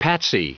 Prononciation du mot patsy en anglais (fichier audio)
Prononciation du mot : patsy